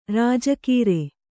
♪ rāja kīre